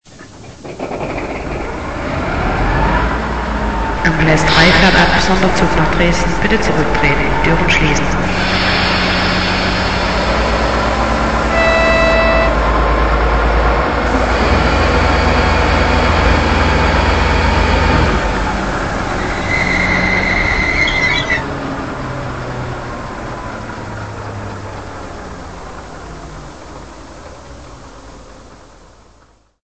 Dźwieki do lokomotyw PKP